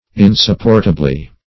Meaning of insupportably. insupportably synonyms, pronunciation, spelling and more from Free Dictionary.